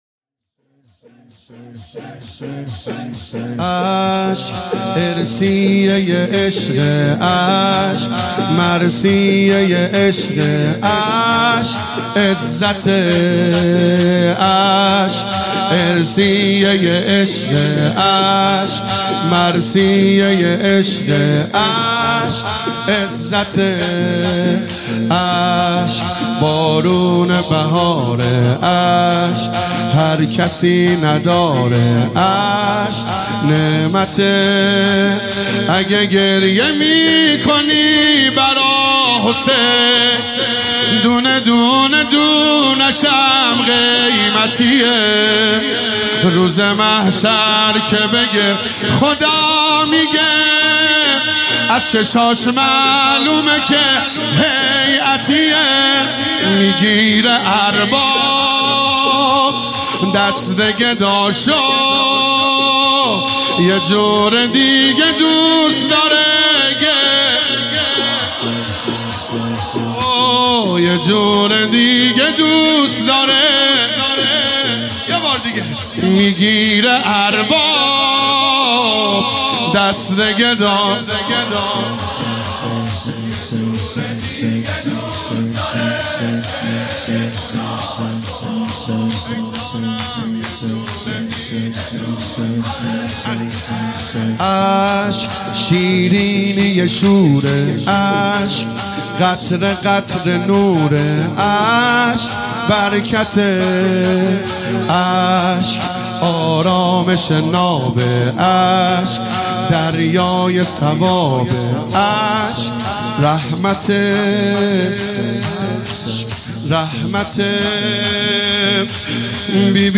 هیئت یا فاطمه الزهرا (س) بابل
شور
نوحه محرم